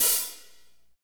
Index of /90_sSampleCDs/Northstar - Drumscapes Roland/HAT_Hi-Hats/HAT_P_B Hats x
HAT P B S0GL.wav